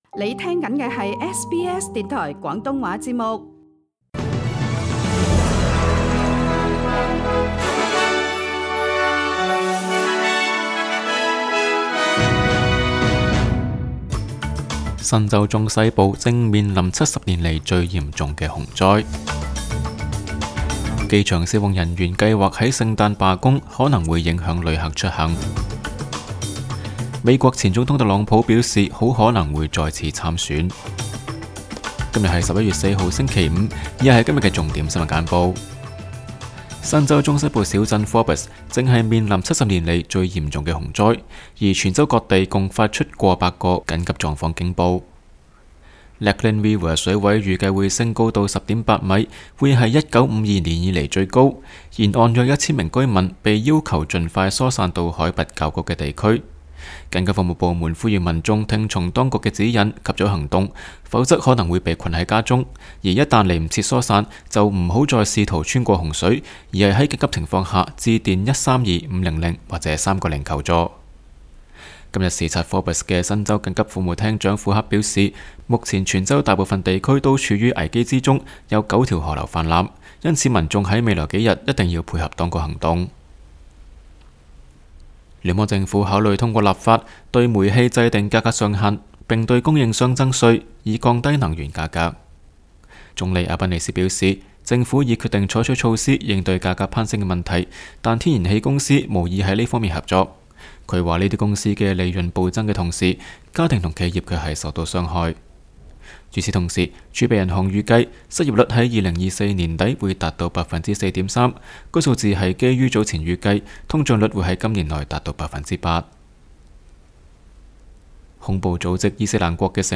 SBS 廣東話節目新聞簡報 Source: SBS / SBS Cantonese